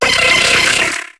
Cri de Noeunoeuf dans Pokémon HOME.